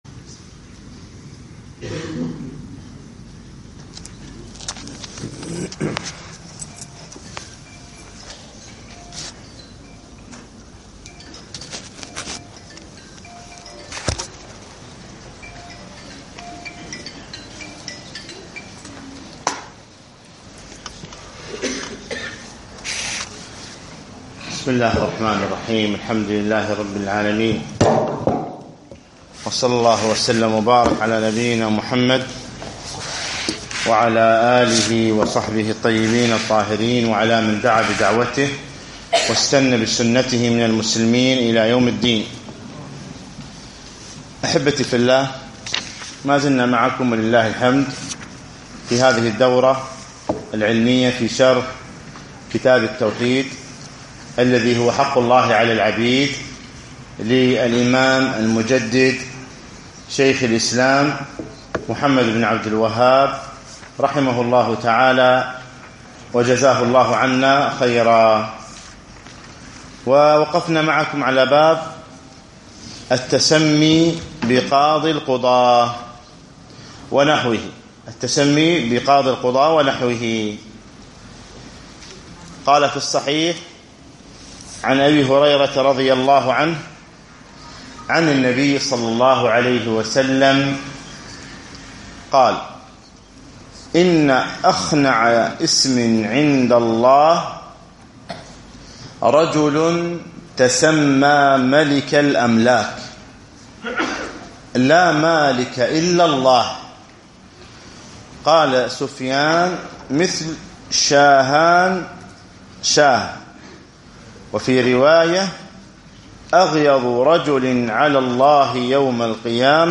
الدرس السابع والعشرون